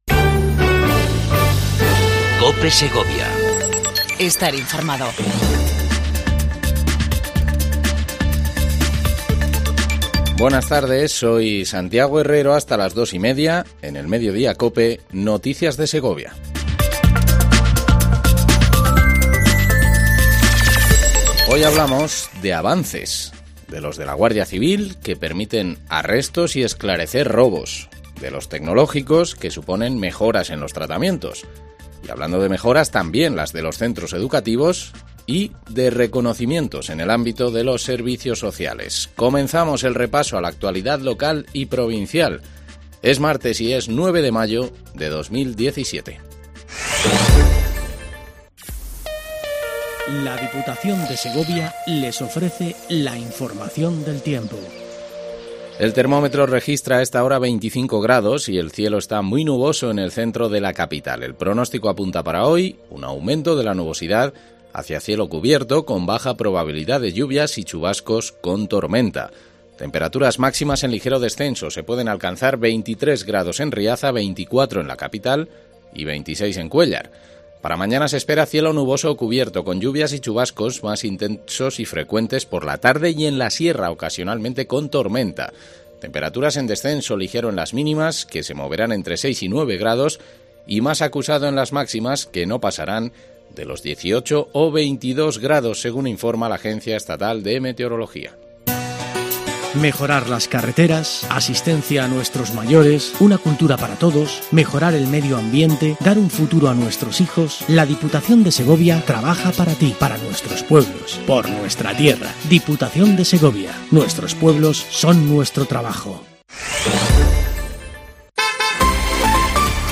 INFORMATIVO MEDIODIA COPE EN SEGOVIA 09 05 17